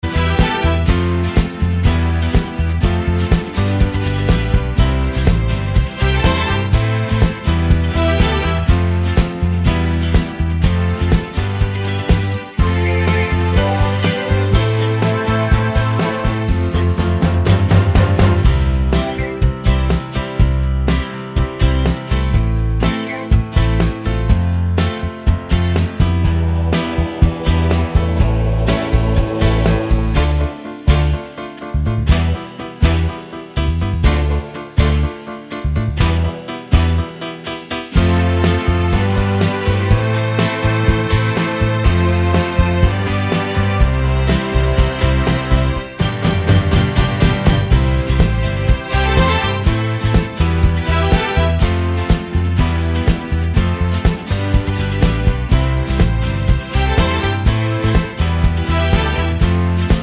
no Backing Vocals or vocoder Pop (1970s) 3:49 Buy £1.50